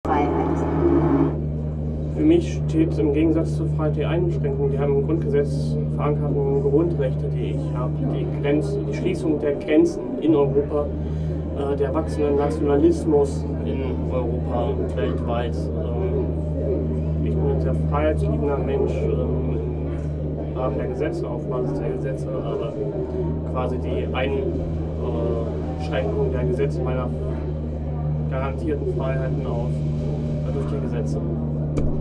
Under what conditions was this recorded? Stendal 89/90 @ Stendal Standort war das Stendal.